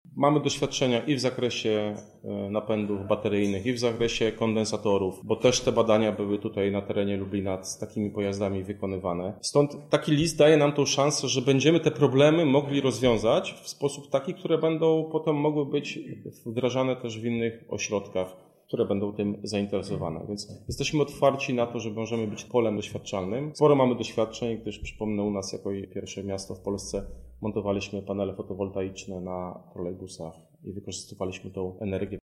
O planowanych działaniach mówi zastępca prezydenta Lublina Artur Szymczyk: